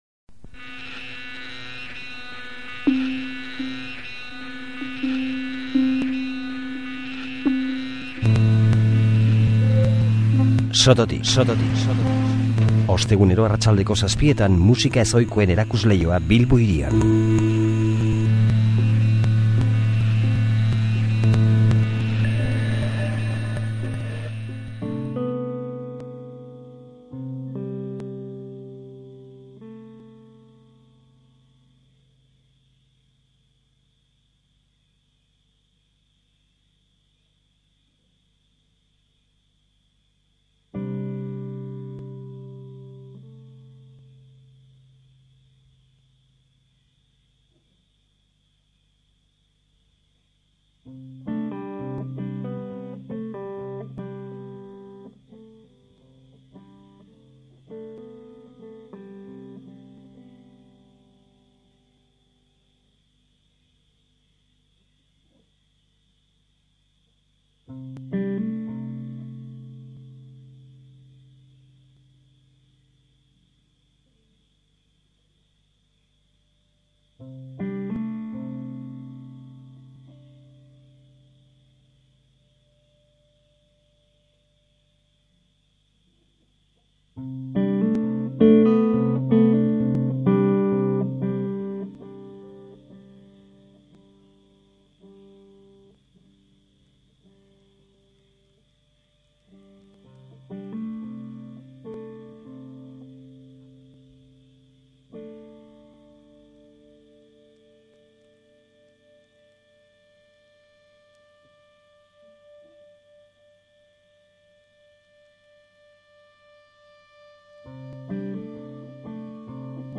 Zarauztarren musika goxoa eta definitu ezina